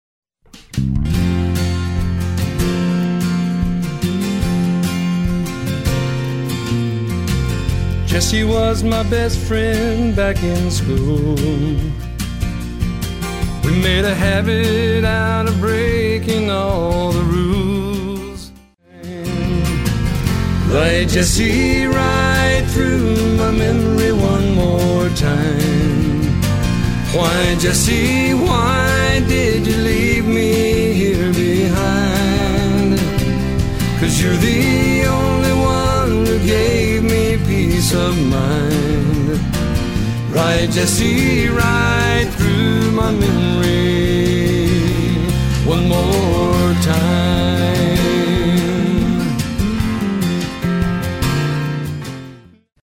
Recorded in Nashville in 2000